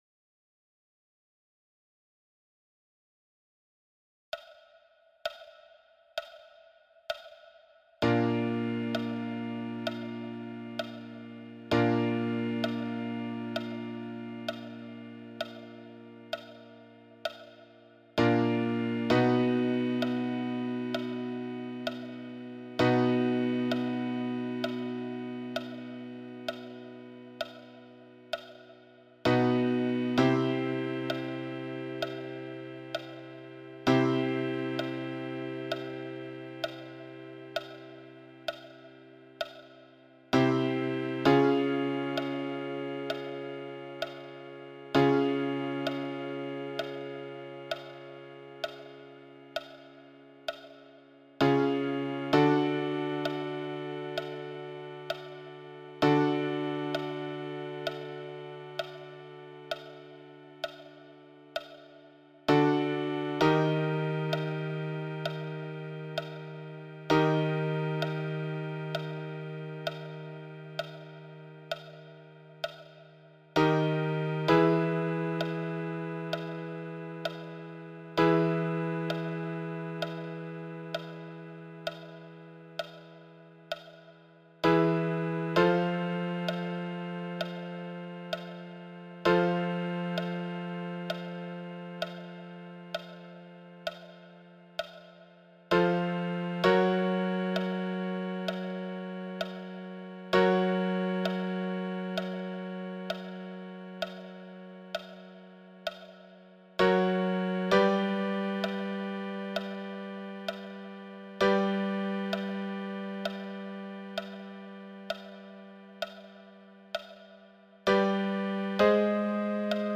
Technical Exercises
buzzing-e28691.mp3